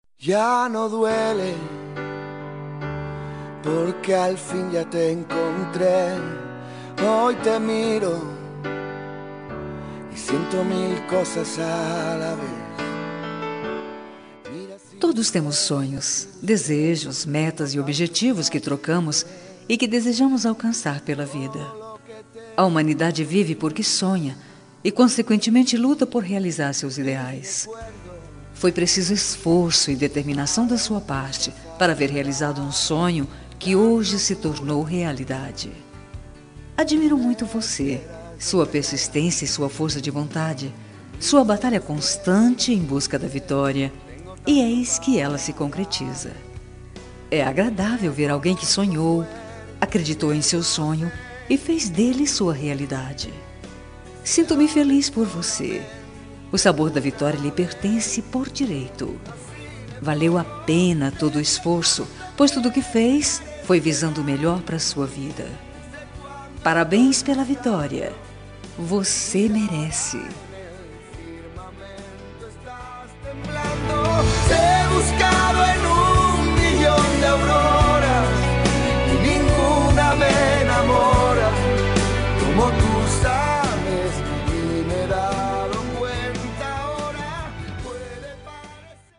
Telemensagem Conquista Vitória – Voz Feminina – Cód: 8148